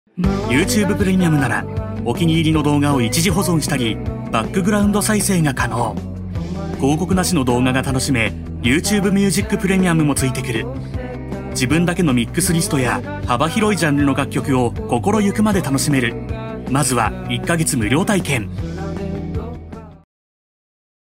Male
20s, 30s, 40s, 50s, 60s
Tokyo standard accent (native)
Microphone: Lewitt LCT 441 FLEX